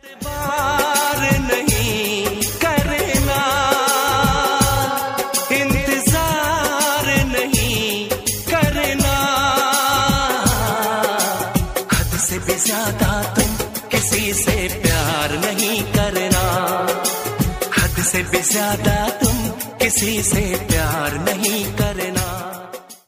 Category: Bollywood Ringtones